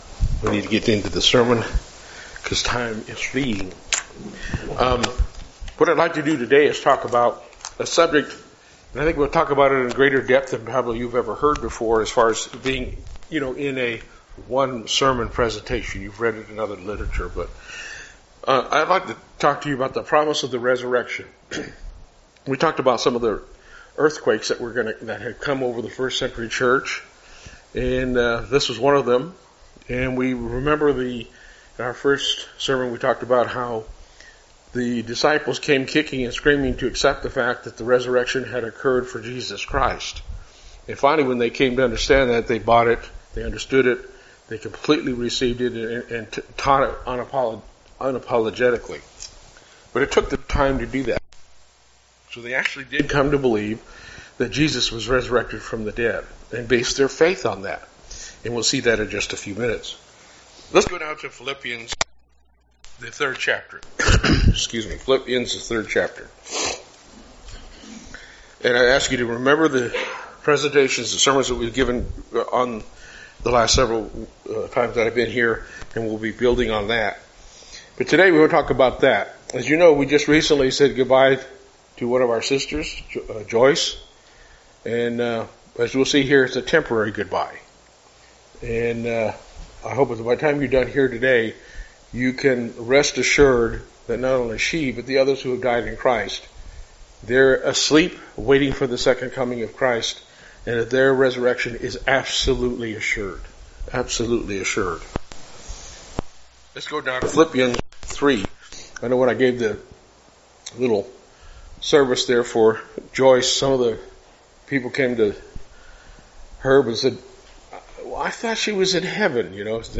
Sermons
Given in Lubbock, TX